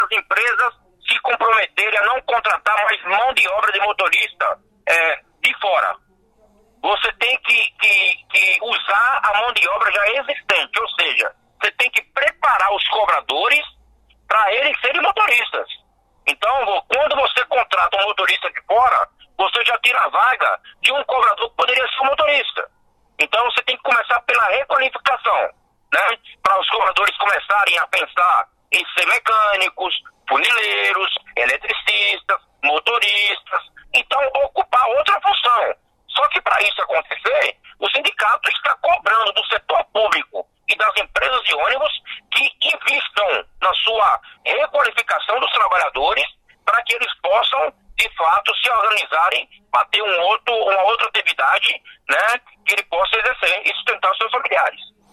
ENTREVISTAS: Em primeira reunião de Comissão sobre cobradores de ônibus, secretário Edson Caram garante que profissionais não vão ser demitidos em São Paulo